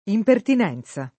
[ impertin $ n Z a ]